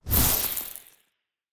Free Frost Mage - SFX
forst_nova_short_01.wav